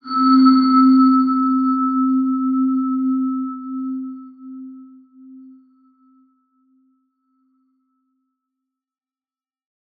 X_BasicBells-C2-ff.wav